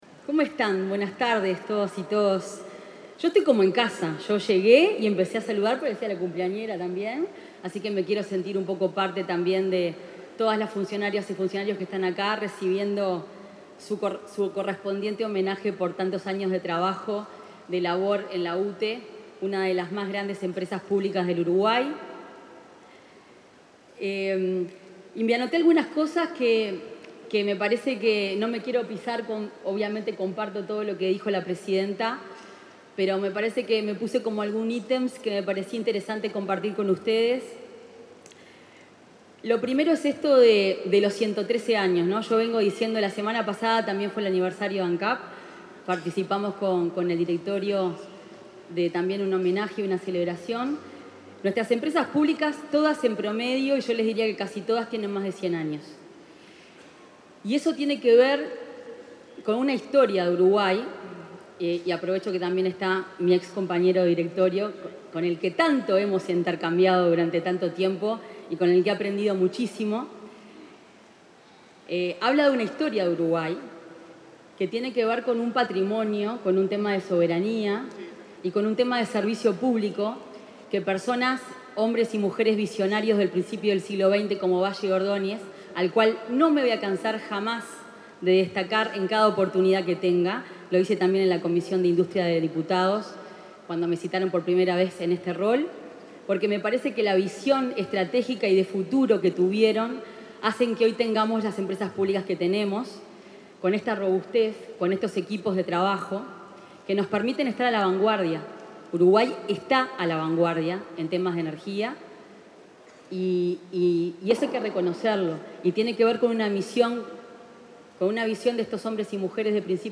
Palabras de autoridades en acto aniversario de UTE
La ministra de Industria, Energía y Minería, Fernanda Cardona, y la presidenta de UTE, Andrea Cabrera, expusieron en la celebración del 113.°
oratoria.mp3